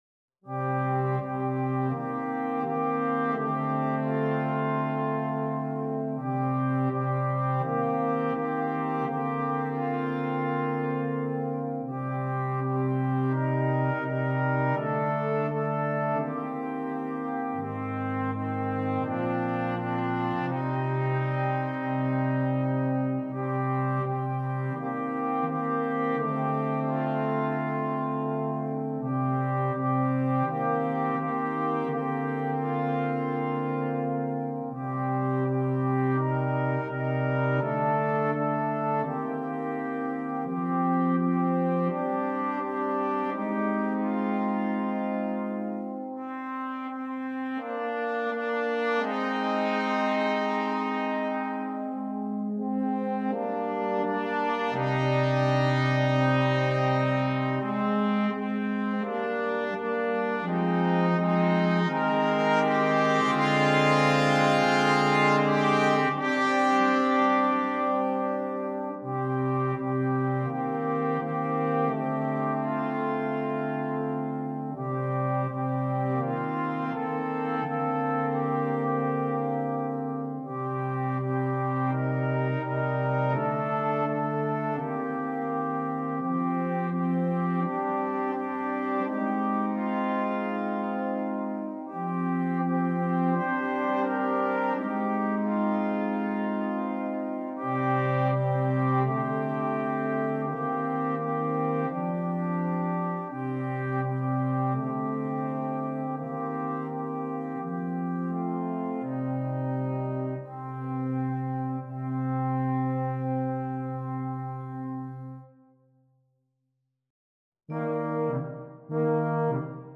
2. Junior Band (flex)
4 Parts & Percussion
without solo instrument
Entertainment
Percussion